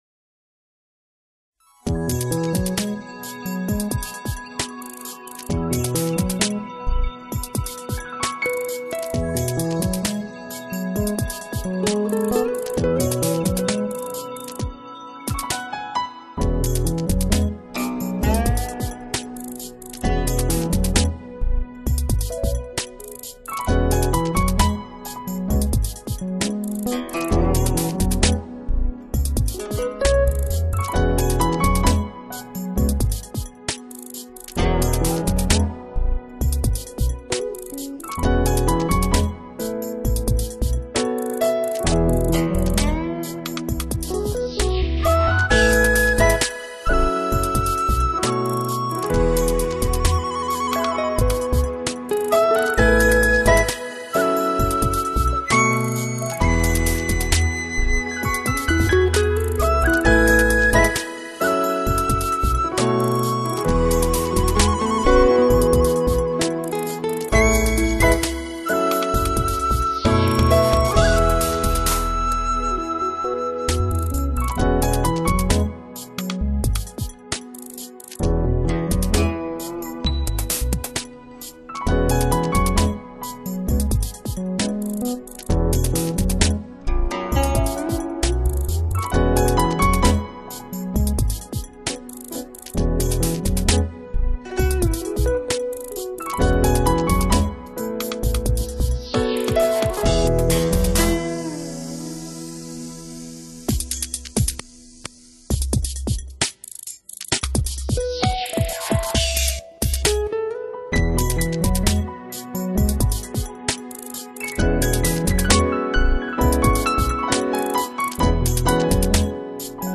CD中12首曲子，总体来说主要体现钢琴的演奏，但风格多样化，从中你会找到你所喜欢的音乐。
这是一张清丽美妙的jazz fusion唱片，浓郁的lounge气息直教人陶醉。
竹笛，古筝和钢琴，素描一般勾勒出了一派夏日皇城的古朴意境。